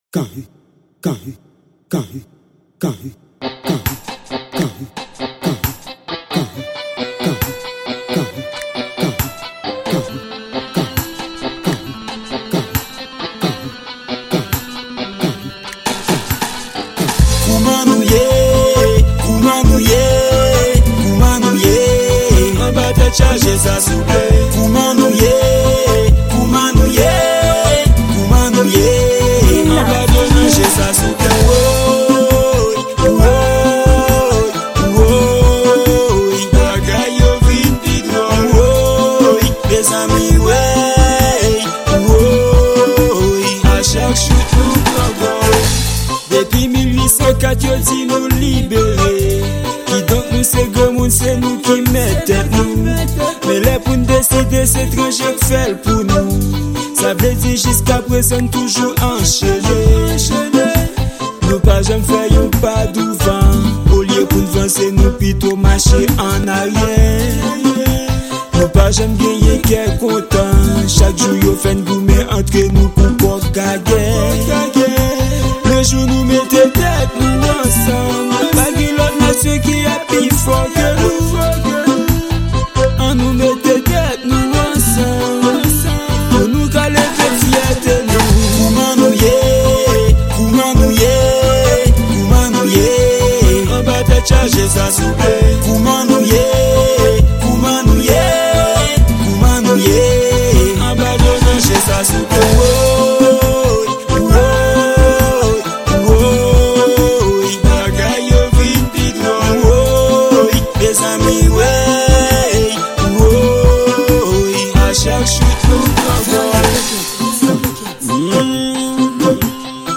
Genre: Raggae.